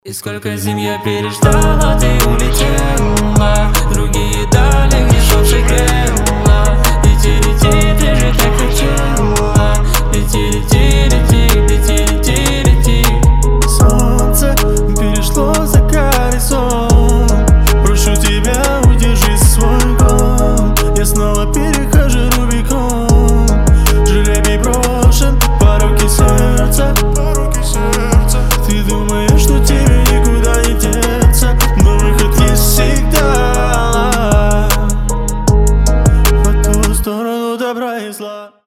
• Качество: 320, Stereo
красивые
лирика
грустные
дуэт